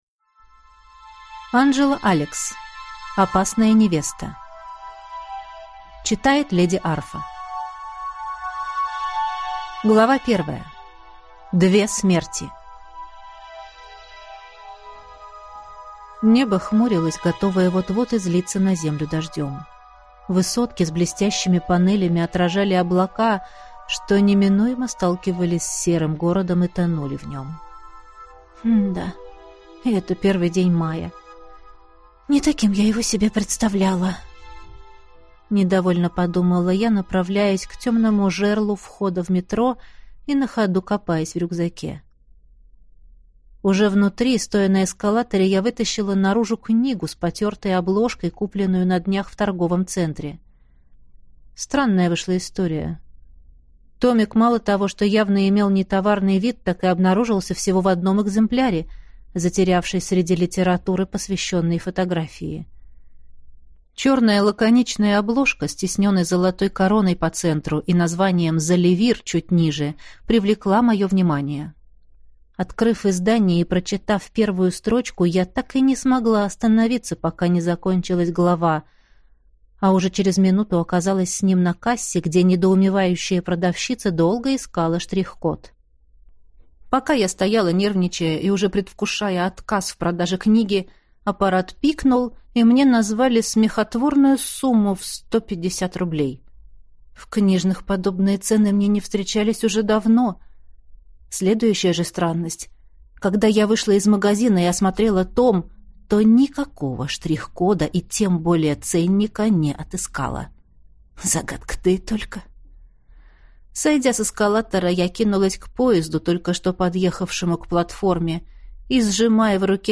Аудиокнига Опасная невеста | Библиотека аудиокниг
Прослушать и бесплатно скачать фрагмент аудиокниги